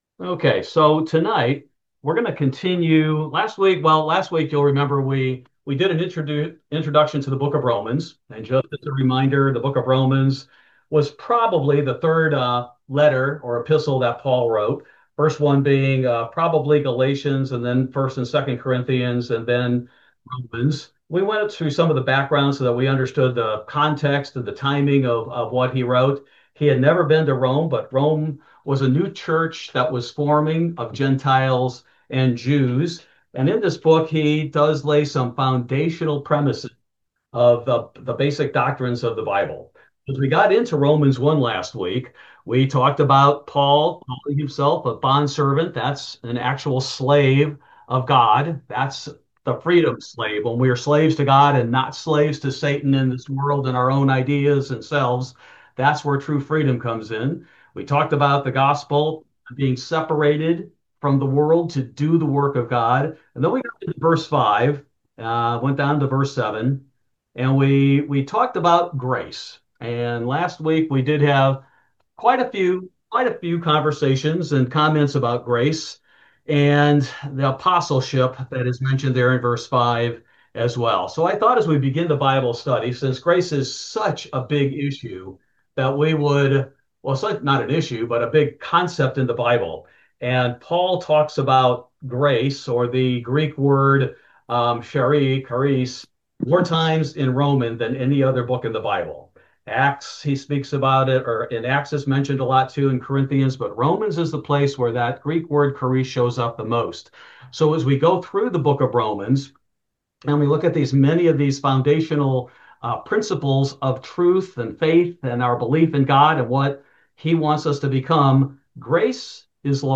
Bible Study: June 11, 2025